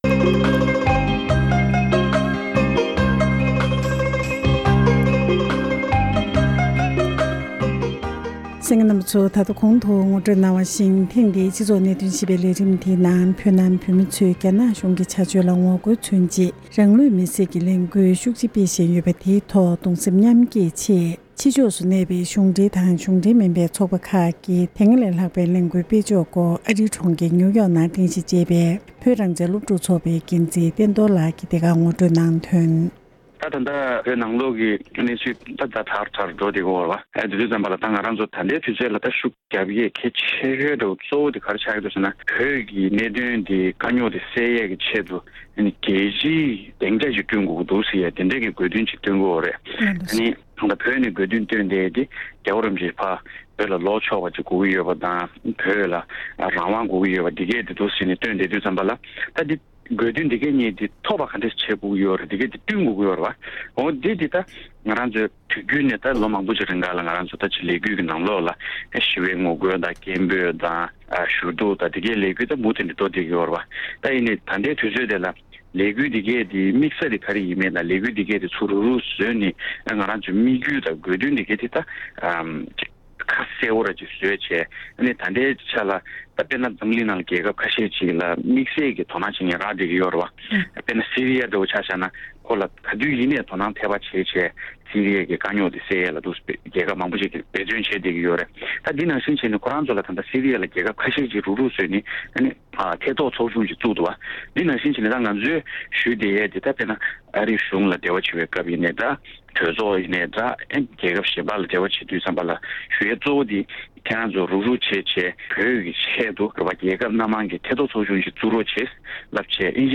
འབྲེལ་ཡོད་མི་སྣར་གནས་འདྲི་ཞུས་པ་ཞིག་ལ་གསན་རོགས་ཞུ༎